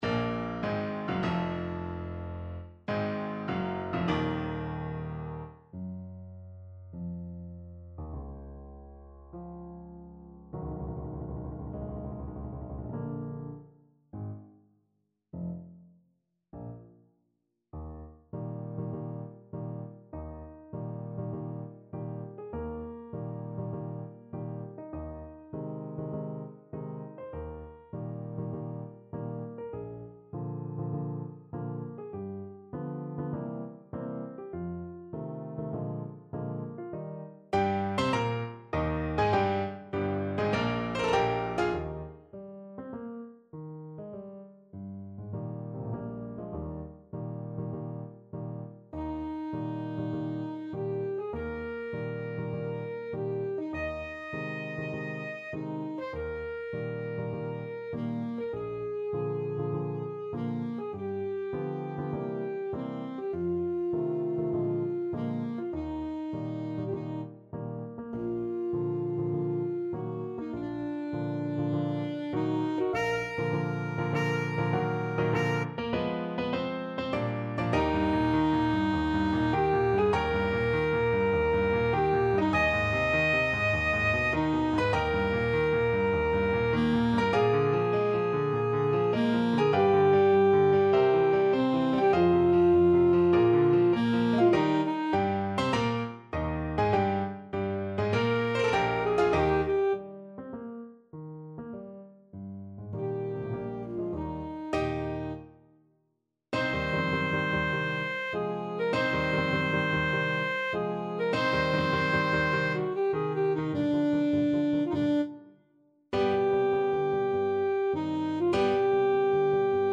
Alto Saxophone version
Alto Saxophone
4/4 (View more 4/4 Music)
Allegro maestoso (=100) (View more music marked Allegro)
Classical (View more Classical Saxophone Music)